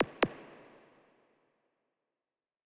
KIN Zap 2.wav